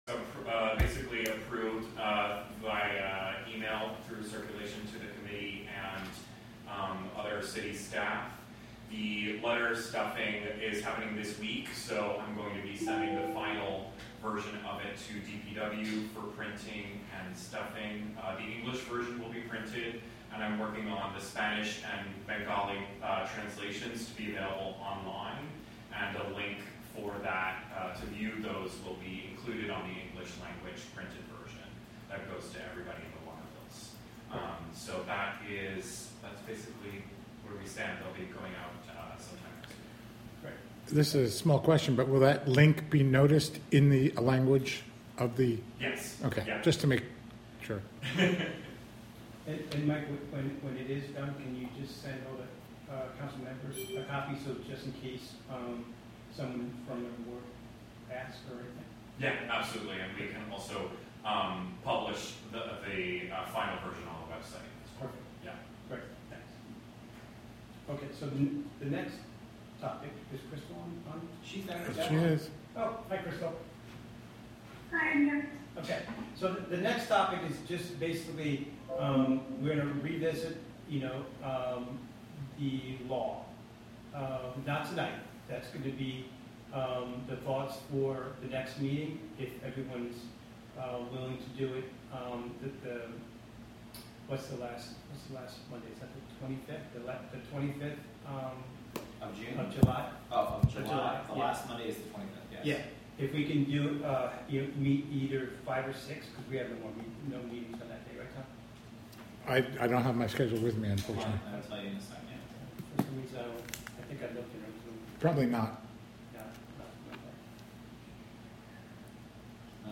Live from the City of Hudson: Hudson Common Council (Audio)